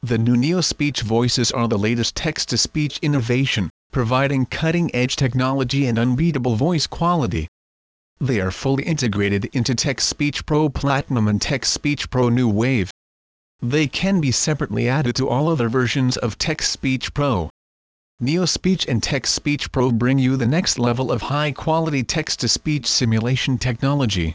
Text-to-Speech SDK for Windows Mobile (R) :: Digital Future Software Company
Voice Demo
Paul 16k (U.S. English)